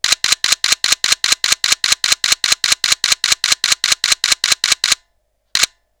Sorozat fényképezés, sebesség
FX RAW sorozat 12 bit
D3X_continousFX12bit.wav